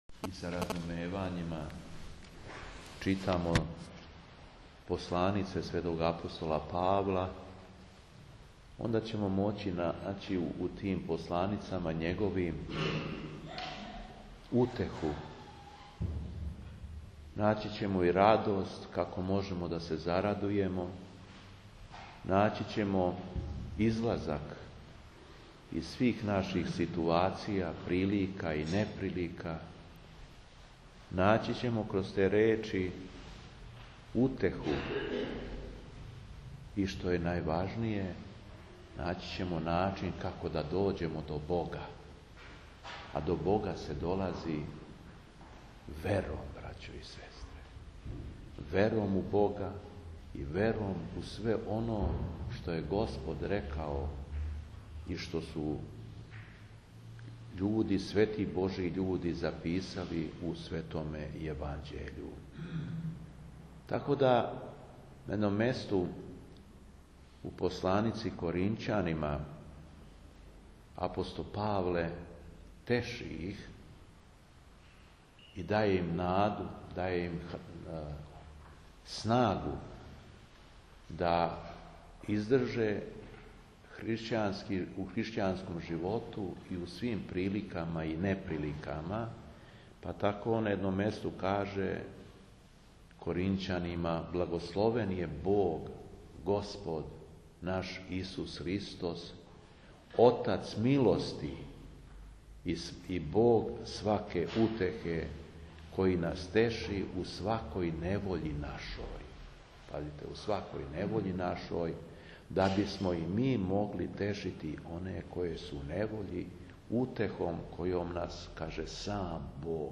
Беседа Епископа шумадијског Г. Јована
Епископ се након прочитаног Јеванђеља обратио окупљеним верницима између осталог рекавши да је сваком човеку некада потребна утеха и човек може другог човека да утеши али у оноликој мери колико му је дато.